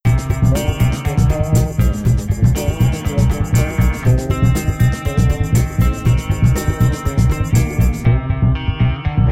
background-music-aac.wav